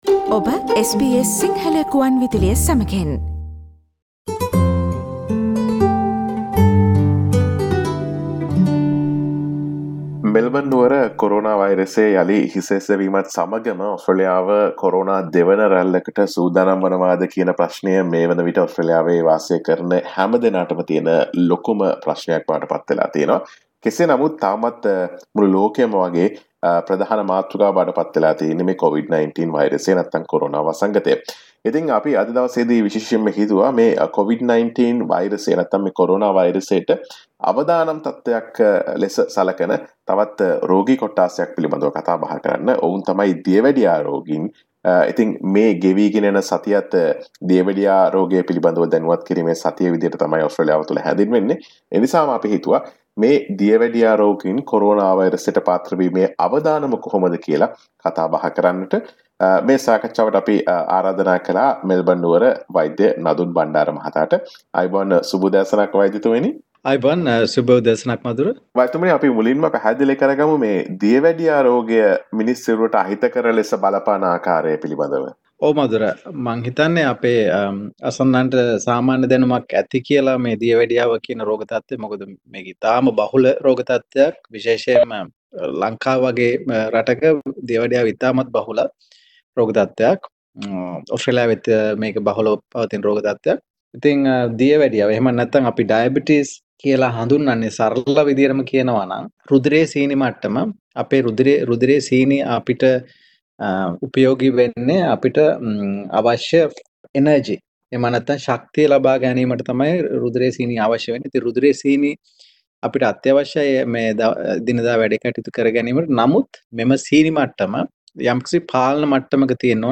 What can people with Diabetes do to protect themselves? SBS Sinhala Radio interview